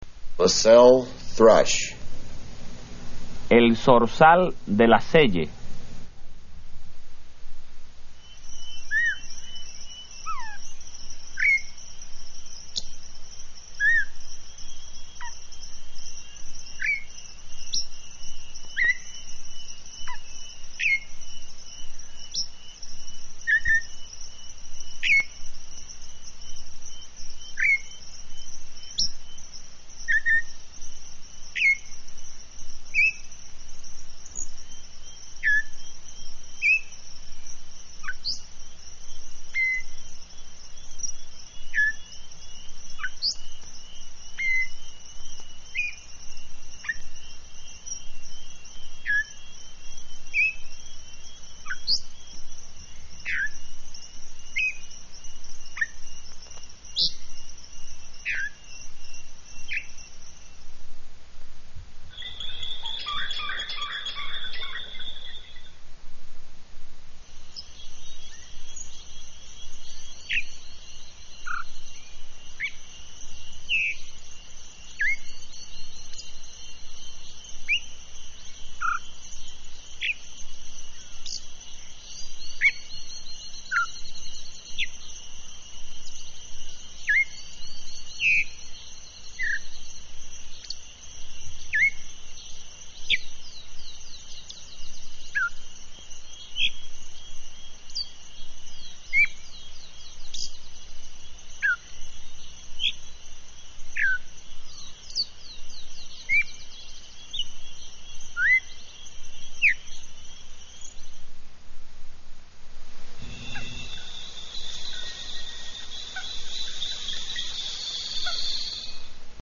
Bird Sounds from Hispaniola
LaSelles-Thrush
LaSelles-Thrush.mp3